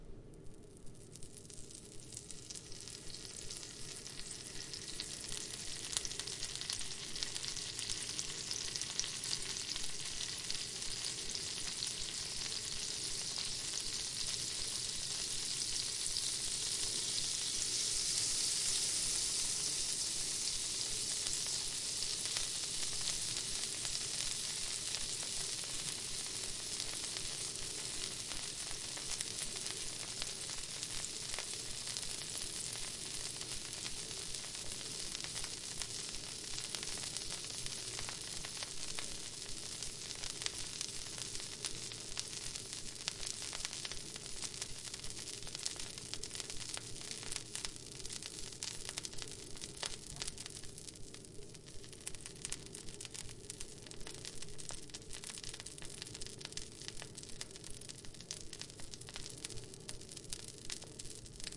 Kitchen Sounds » Taking out frying pan
描述：Kitchen sounds
标签： kitchen pan frying
声道立体声